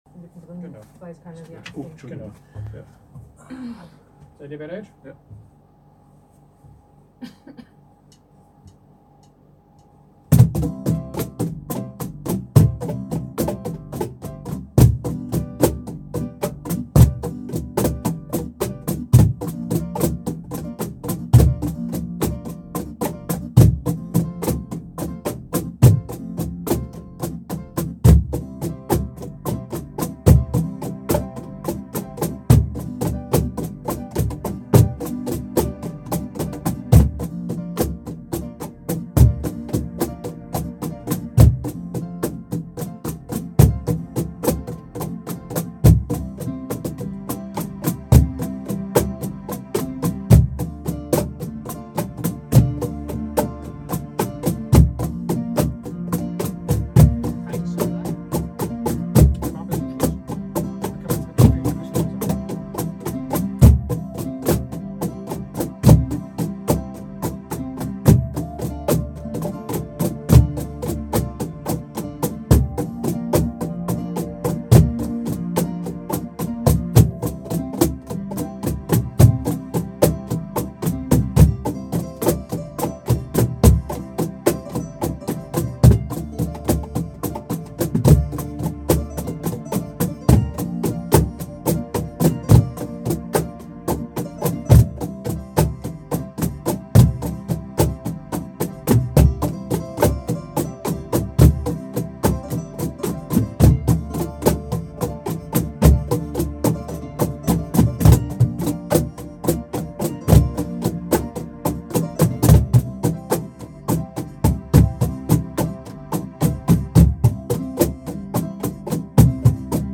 Cajón videos
Rumba - 4 Cajones More video recordings